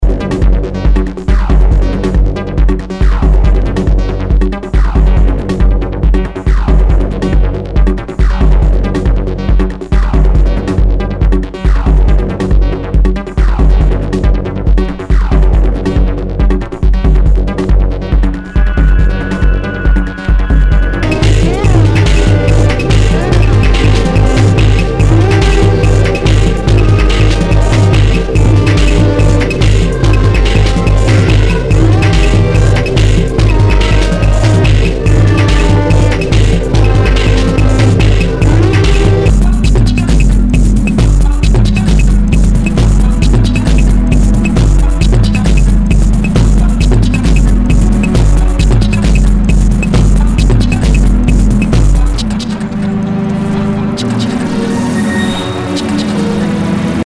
HOUSE/TECHNO/ELECTRO
アシッド・テクノ・クラシック！